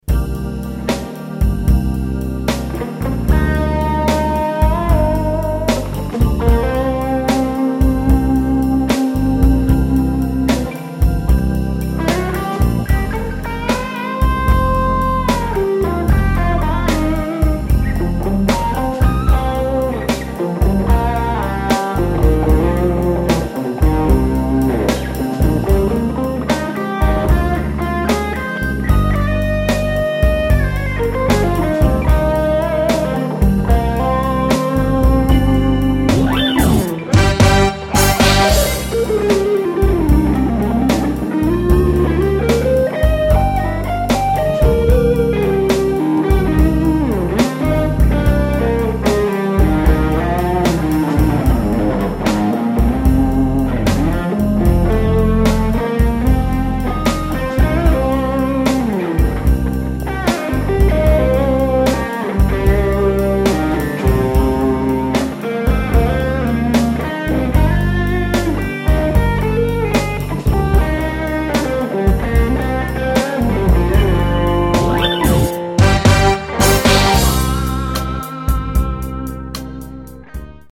Donc j'ai fait quelque chose d'assez sobre et rapide, one shot un son pour les blues.
Vox tonelab SE pour le reste audacity mais j'ai rien retravaillé, c'est du pur pur !
Blues ibanez
Je trouve que les guitares sont un peu loin dans le mix.
blues-essai-mixrapide-ibanez.mp3